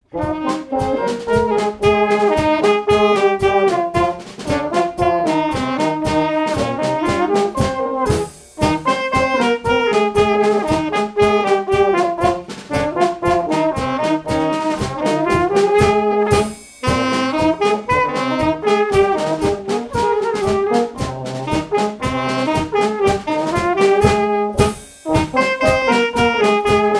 danse : quadrille : galop
Répertoire pour un bal et marches nuptiales
Pièce musicale inédite